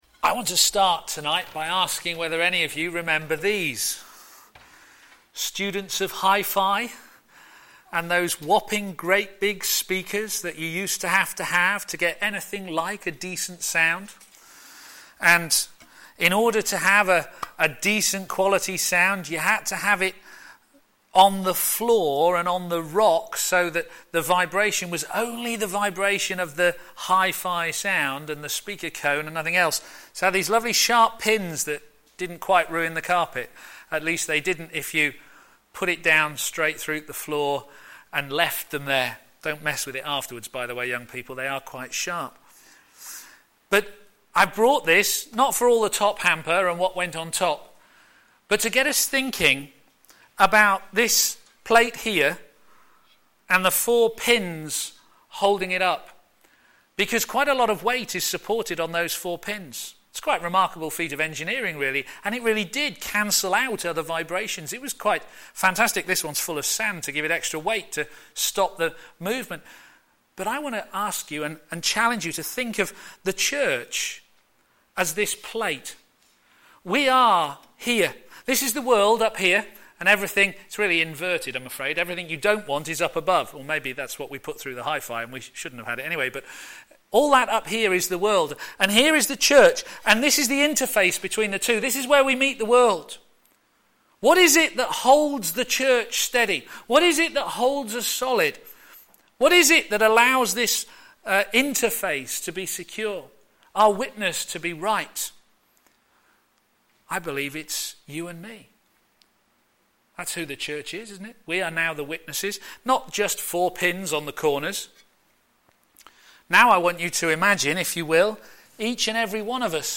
Media for p.m. Service
Sermon